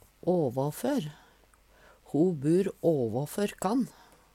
åvåfør - Numedalsmål (en-US)